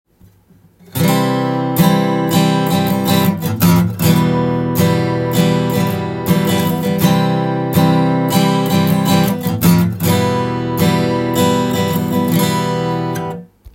アコースティックギターでも
C　/　　Em のようなコード進行前に６弦１フレットのオクターブ奏法を使用できます。
まるでベースラインをギターが弾いているかのような雰囲気がします。